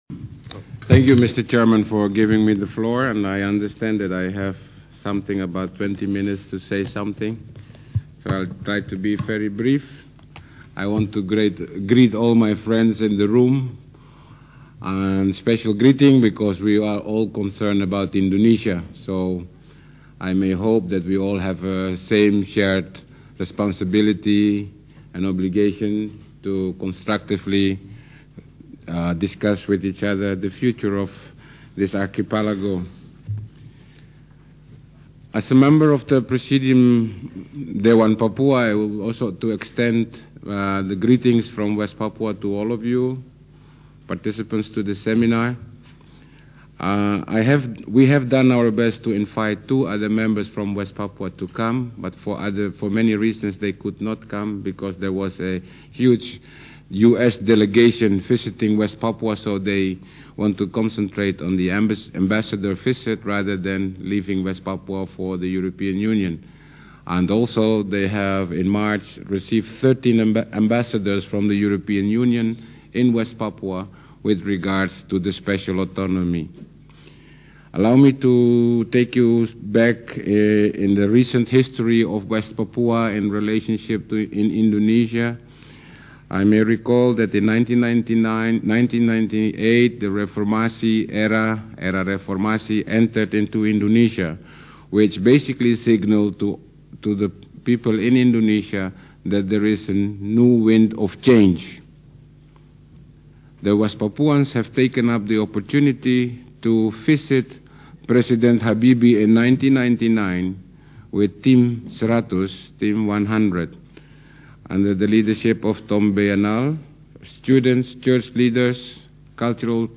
Presentations